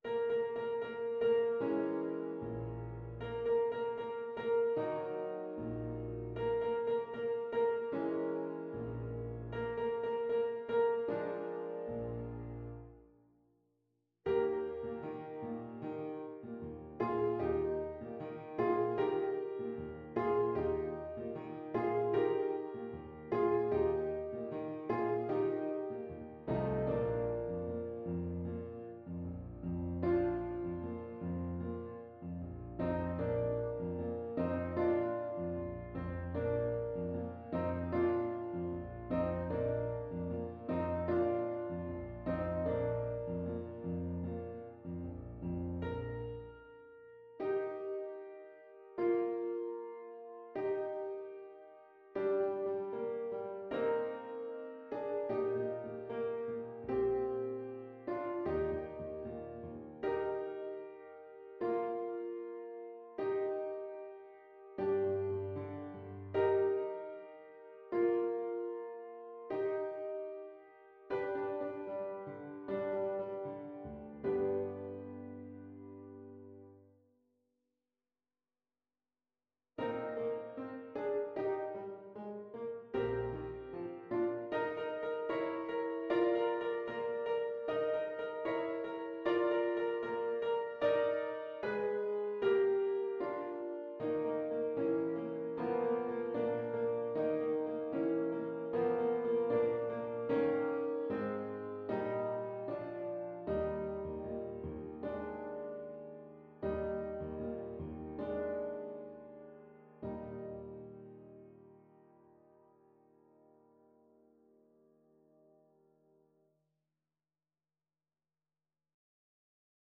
Play (or use space bar on your keyboard) Pause Music Playalong - Piano Accompaniment Playalong Band Accompaniment not yet available reset tempo print settings full screen
Eb major (Sounding Pitch) F major (Clarinet in Bb) (View more Eb major Music for Clarinet )
=76 Allegretto lusinghiero =104
Classical (View more Classical Clarinet Music)